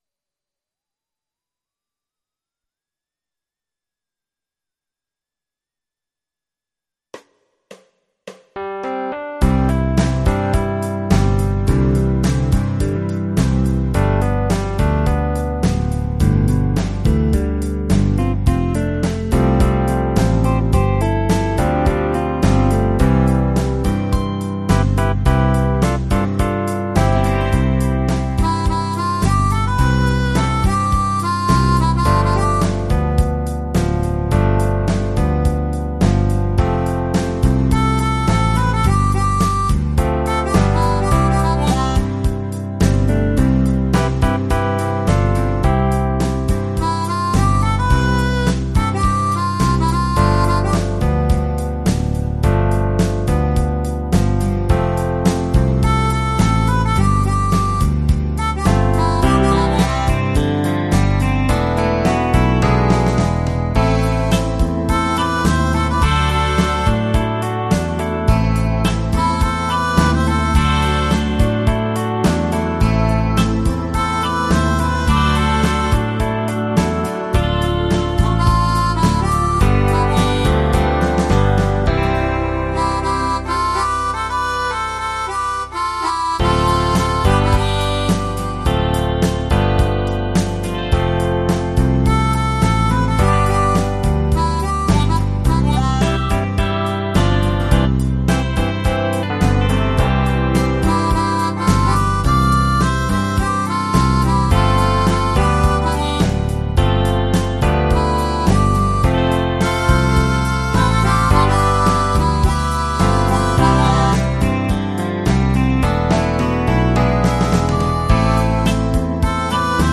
versión instrumental multipista
en formato MIDI Karaoke pro.